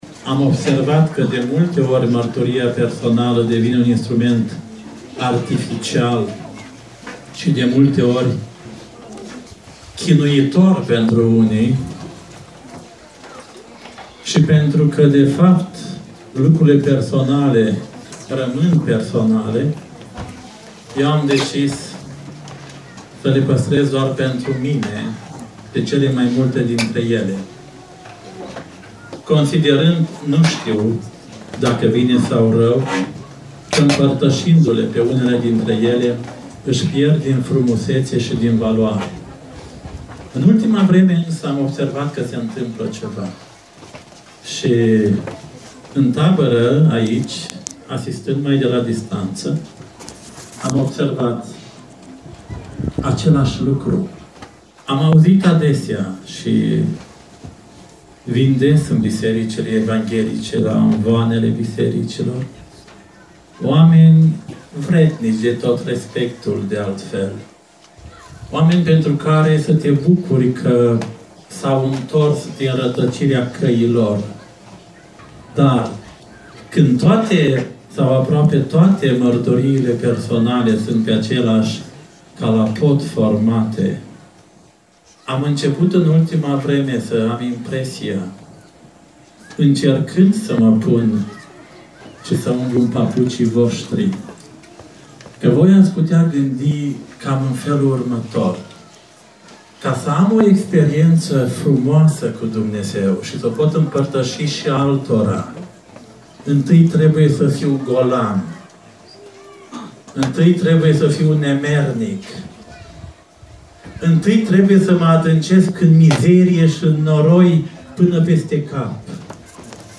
Tabăra organizată de biserica Betel din Rădăuți – ultima seară